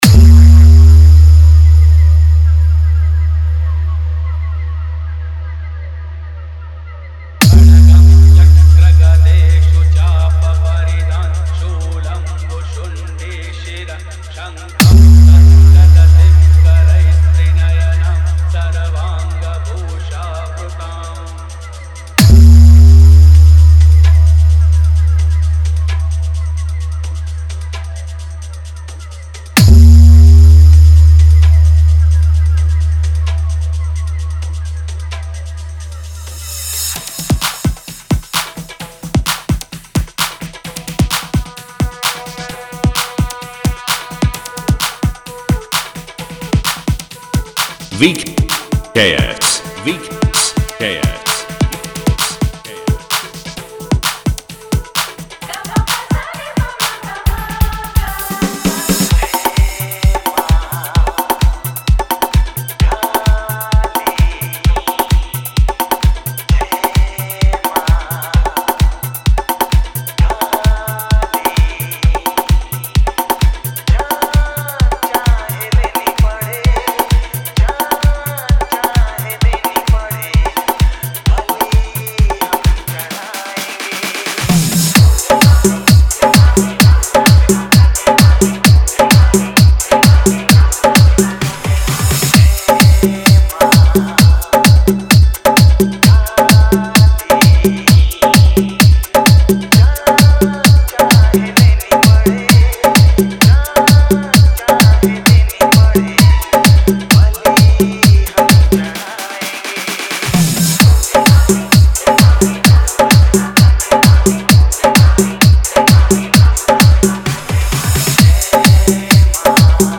Navratri Dj Remix